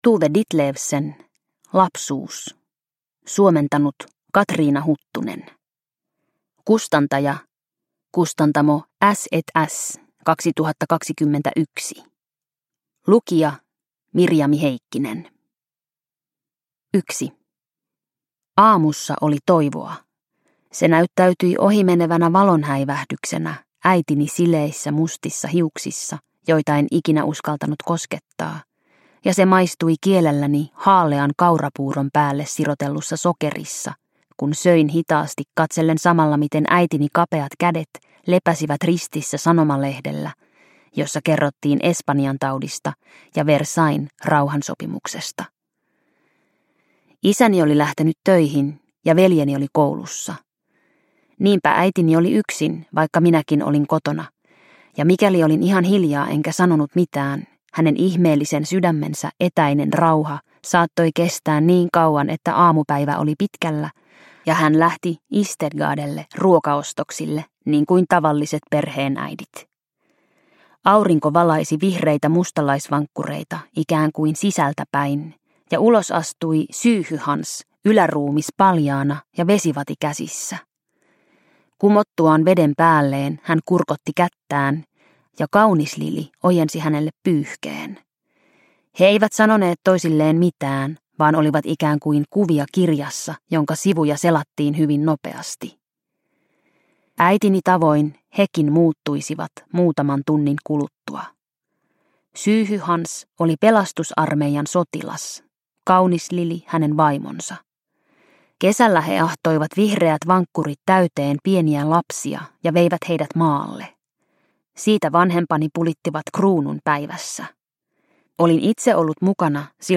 Lapsuus – Ljudbok – Laddas ner